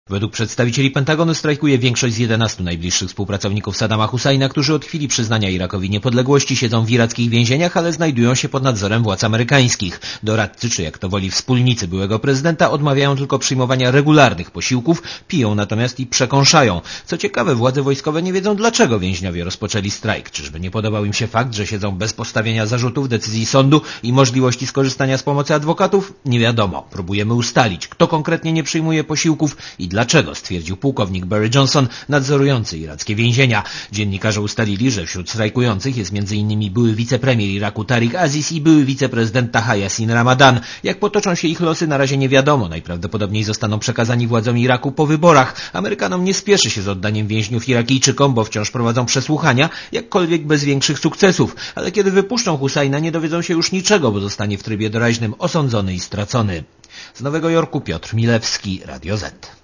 Korespondencja z Nowego Jorku